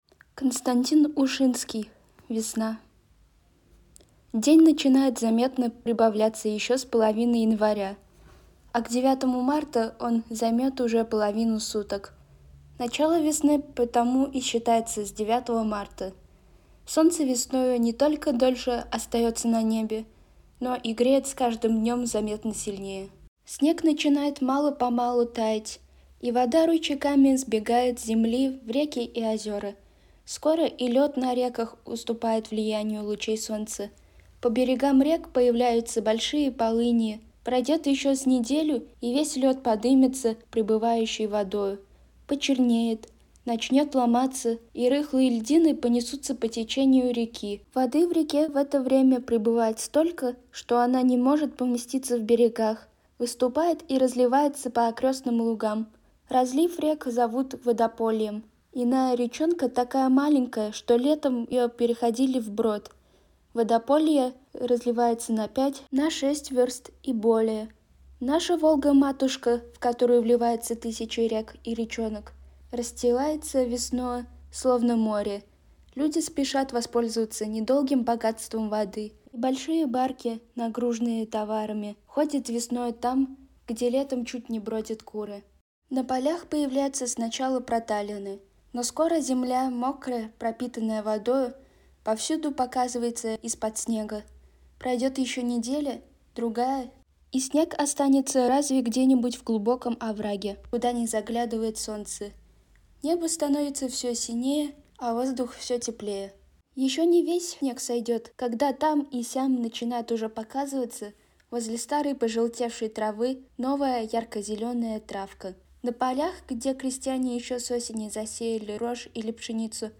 С чем ассоциируется у вас это время года?Предлагаем вам окунуться в мир пробуждающейся природы вместе с рассказом Константина Ушинского «Весна» — а мы добавили к нему немного магии звука! «Весна» — природоведческий рассказ Константина Ушинского. В нём автор описывает, как после долгих холодов в русские леса, поля и деревеньки вновь возвращается тепло.